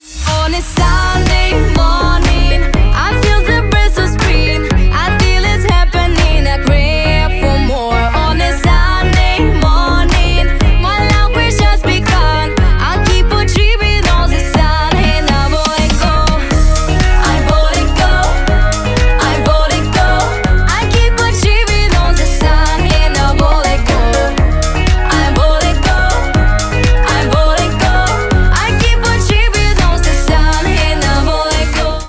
• Качество: 128, Stereo
поп
ритмичные
женский вокал
Очень красивая и ритмичная песня.